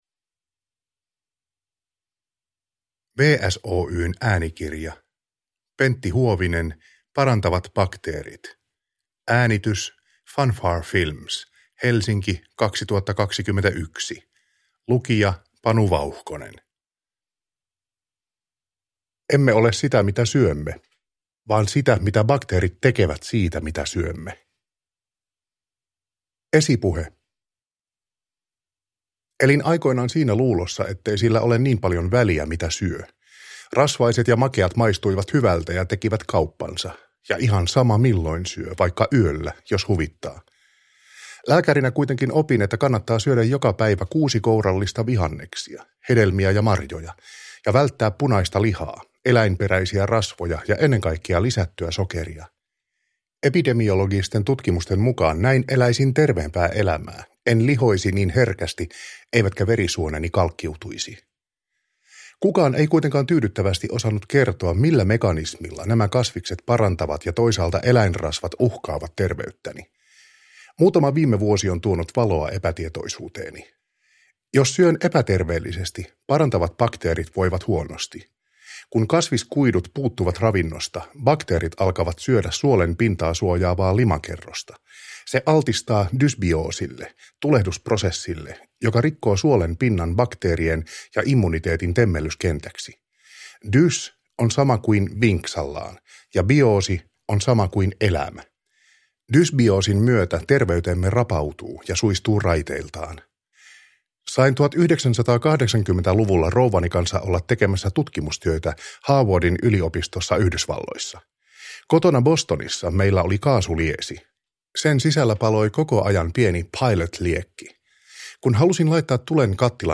Parantavat bakteerit – Ljudbok – Laddas ner